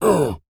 Male_Grunt_Hit_22wav.wav